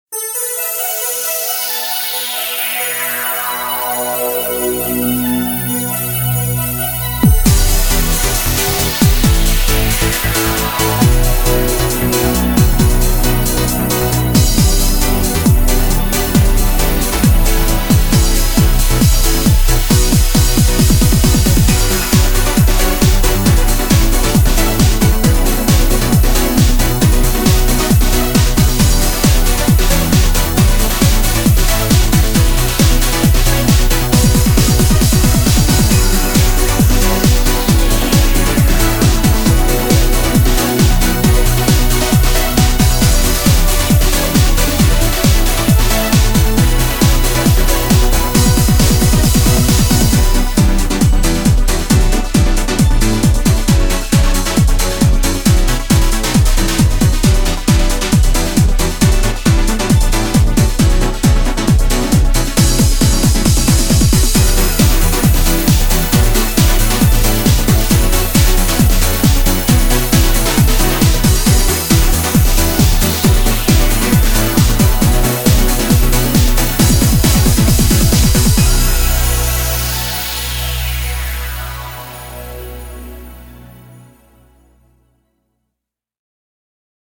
BPM135--1
Audio QualityPerfect (High Quality)